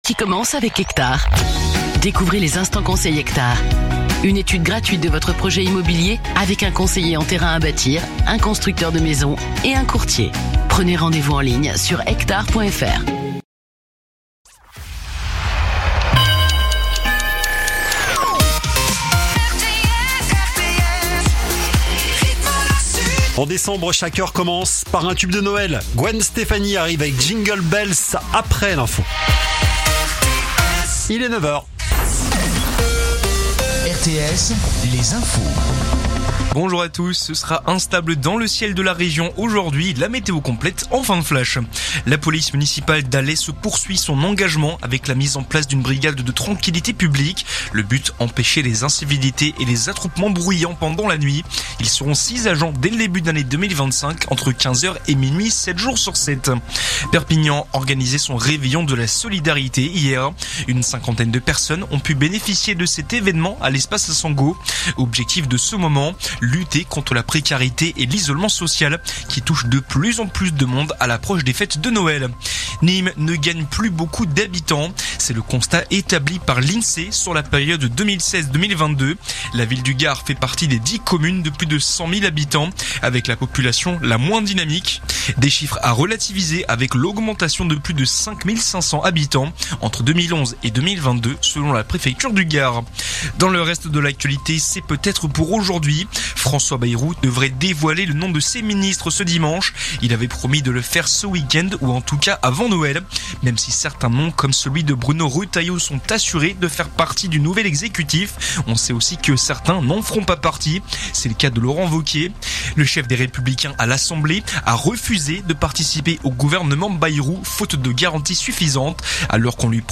Écoutez les dernières actus de Narbonne en 3 min : faits divers, économie, politique, sport, météo. 7h,7h30,8h,8h30,9h,17h,18h,19h.